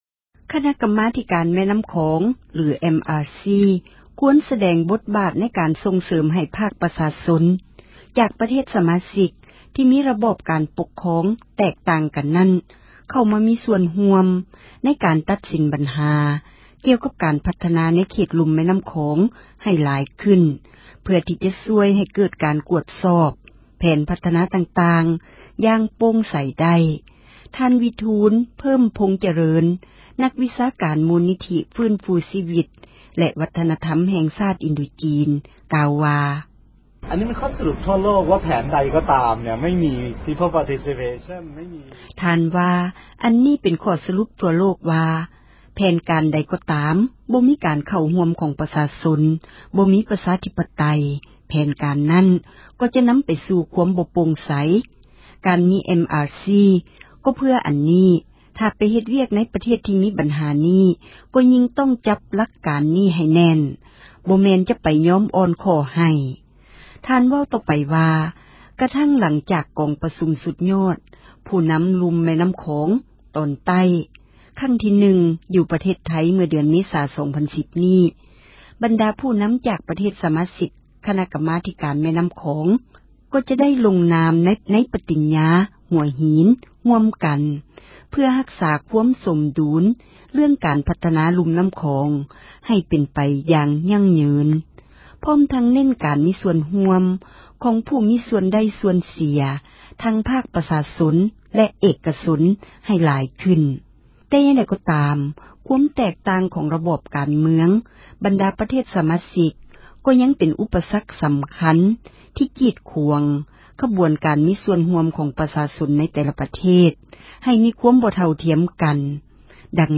ແຜນພັທນານໍ້າຂອງ ຄວນມີປະຊາຊົນ ຮ່ວມນໍາ — ຂ່າວລາວ ວິທຍຸເອເຊັຽເສຣີ ພາສາລາວ